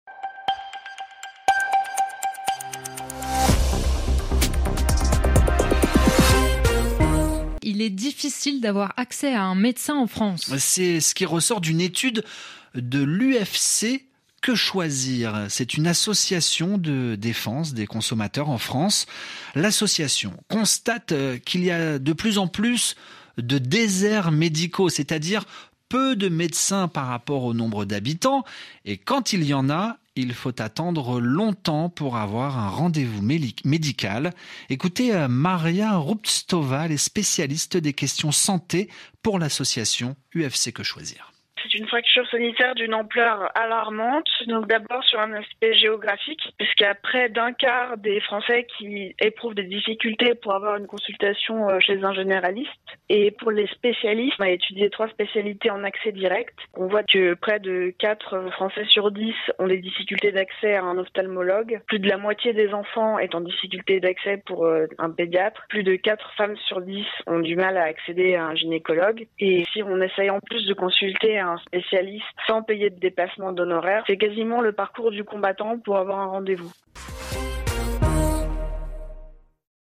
Extrait du Journal en français facile du 08/11/2022 (RFI)